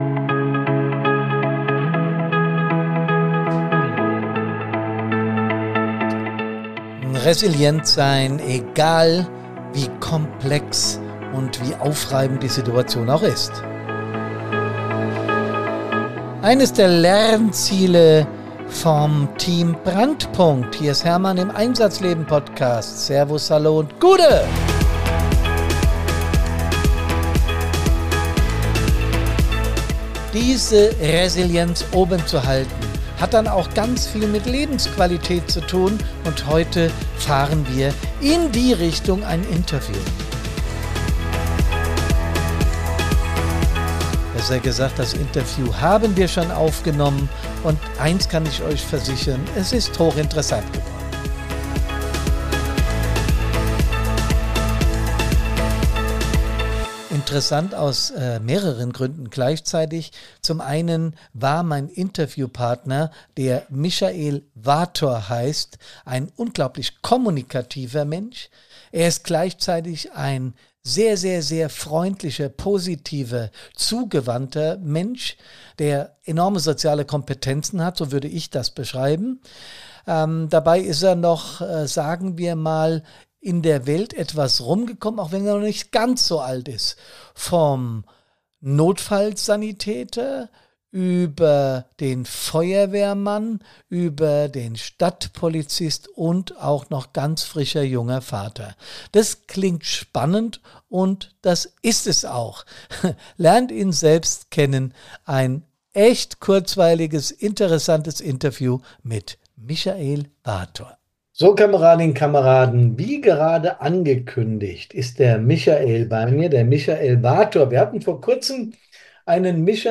Ein Gespräch über gelebte Resilienz, berufliche Entscheidungen und die Kunst, in stürmischen Zeiten den Überblick zu behalten.